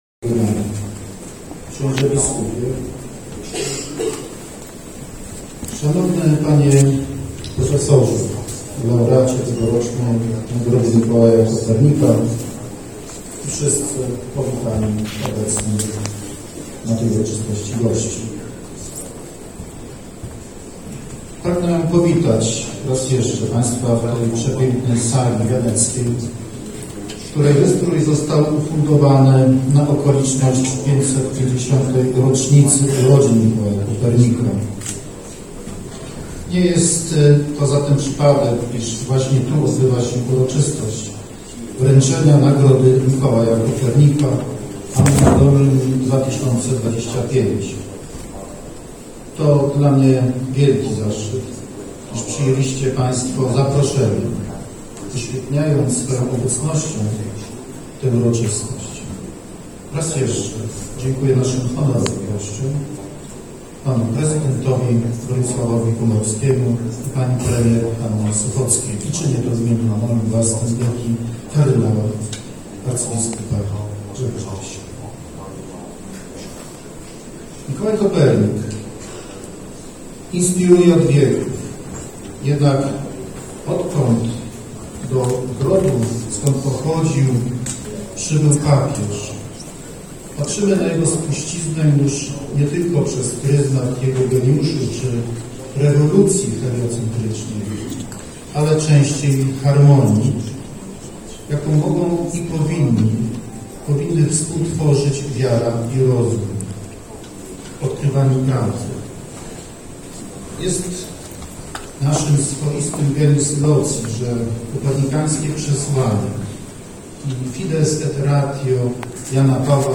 Uroczysta gala w Pałacu Dąmbskich, podczas której prof. Weiler odebrał nagrodę, otworzyła wydarzenia Święta Województwa.
Wystąpienie audio:
marszalek_piotr_calbecki.mp3